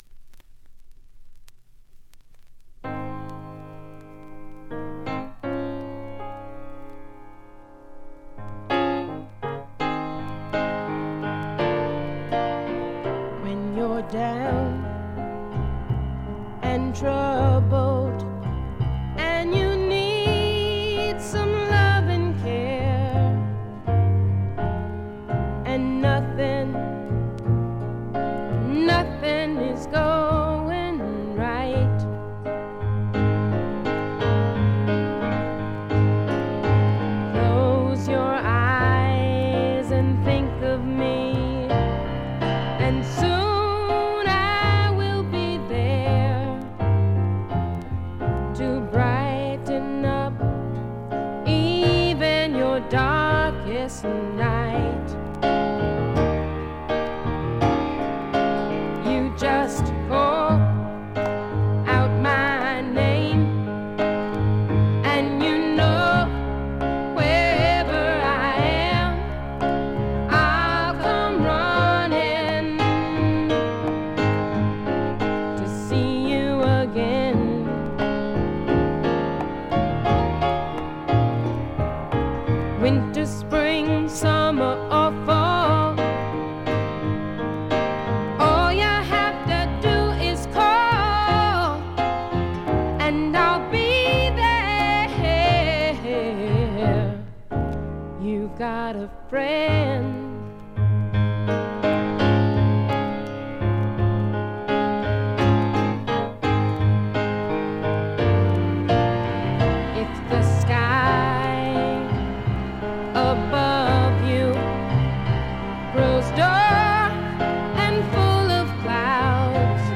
静音部で軽微なバックグラウンドノイズ、チリプチ少々。
試聴曲は現品からの取り込み音源です。